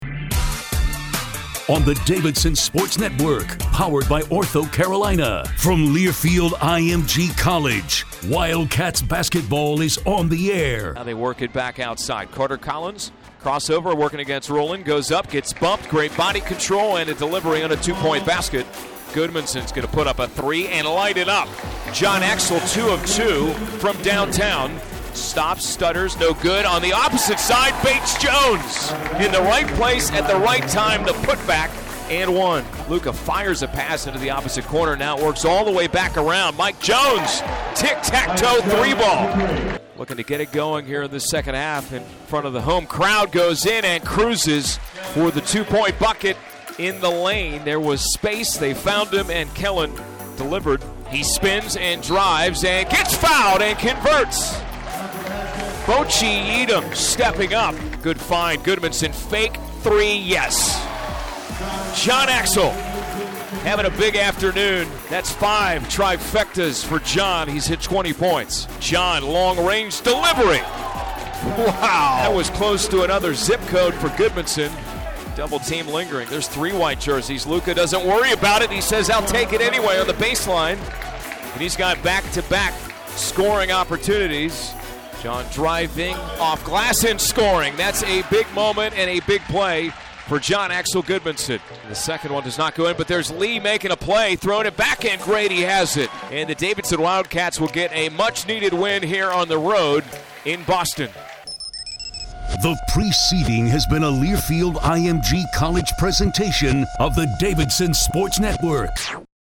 Radio Highlights
Davidson at NE Highlights.mp3